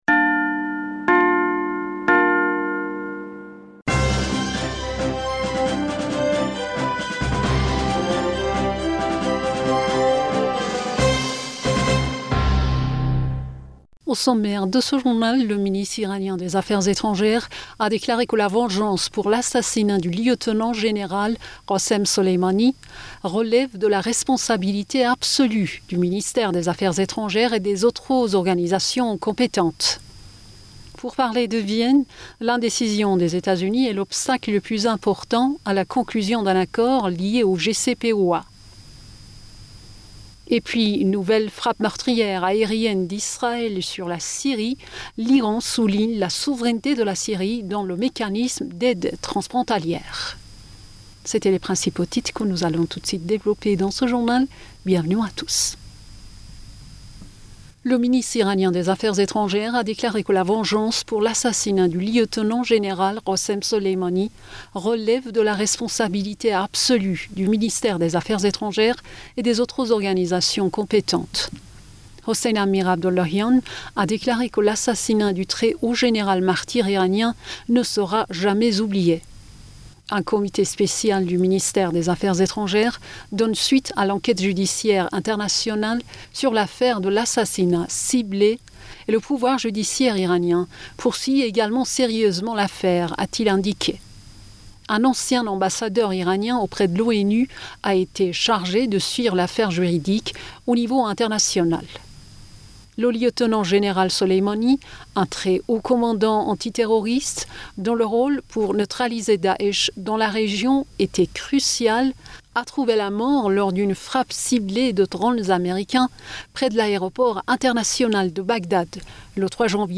Bulletin d'information Du 22 Julliet